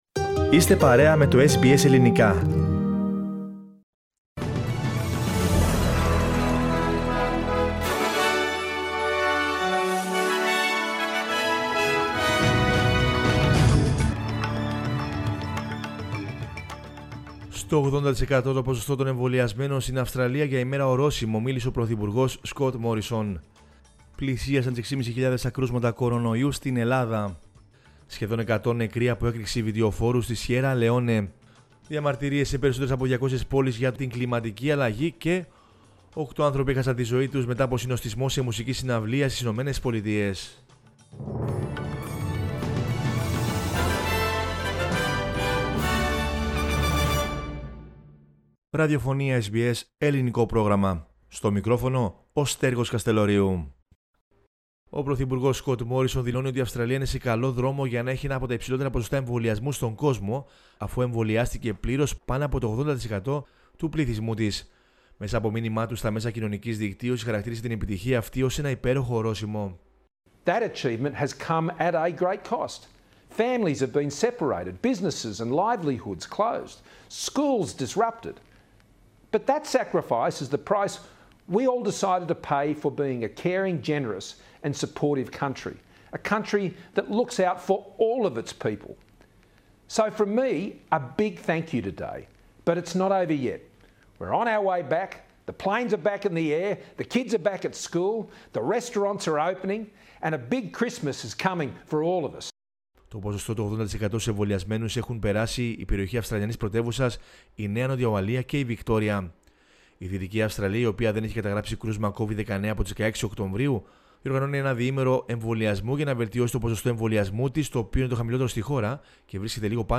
News in Greek from Australia, Greece, Cyprus and the world is the news bulletin of Sunday November 7 2021.